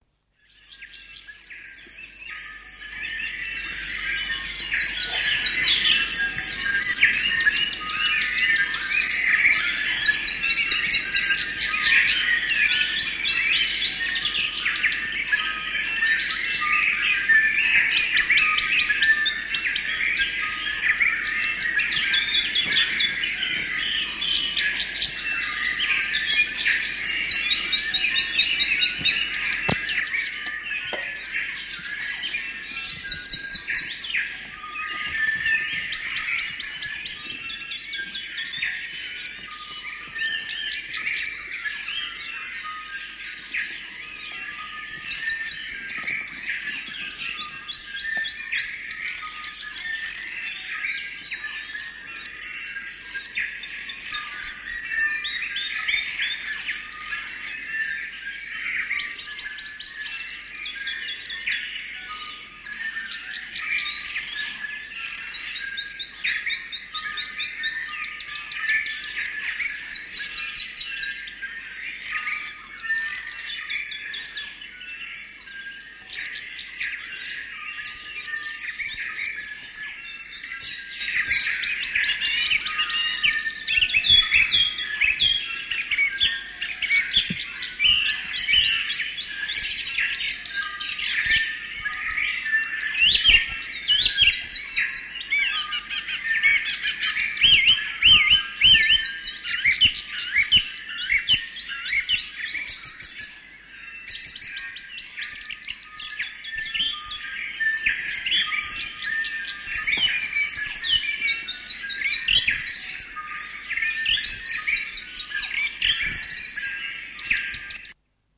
The landing page for Challenge 1 has a link to an audio file of the Dawn Chorus. If you have a listen about 30 seconds there are some Kākā and Pīwakawaka calls which sound like Morse Code.
2. Figure out the Pīwakawaka is a dot and the Kākā is the dash.